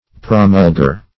Search Result for " promulger" : The Collaborative International Dictionary of English v.0.48: Promulger \Pro*mul"ger\, n. One who promulges or publishes what was before unknown.